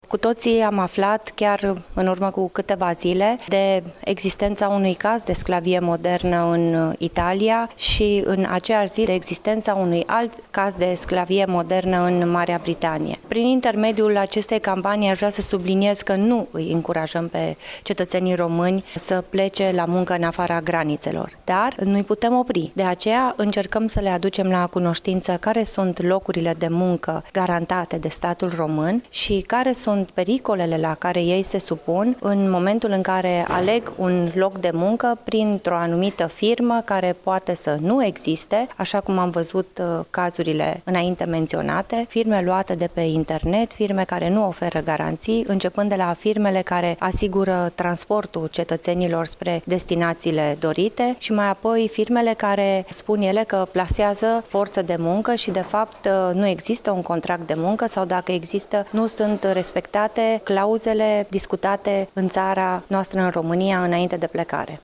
Afirmaţia a fost făcută astăzi, la Iaşi, de ministrul pentru Românii de Pretutindeni, Natalia Intotero.